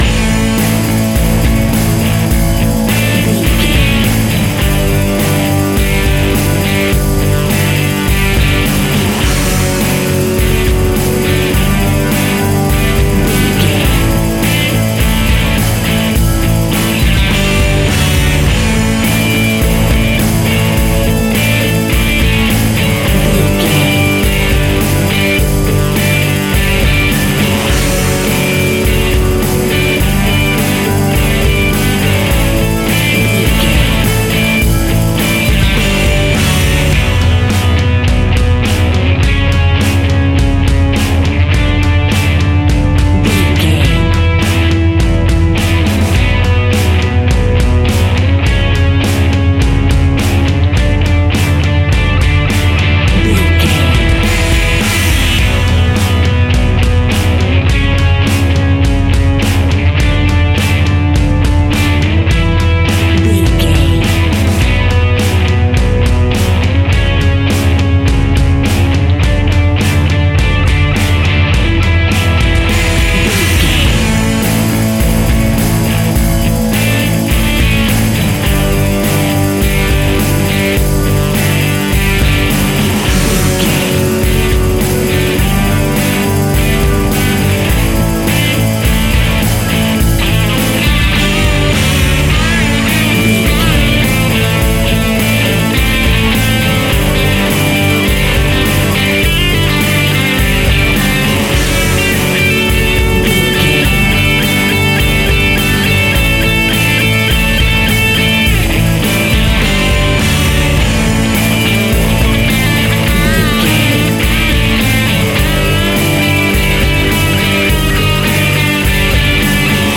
Uplifting
Mixolydian
heavy metal
heavy rock
distortion
Instrumental rock
drums
bass guitar
electric guitar
hammond organ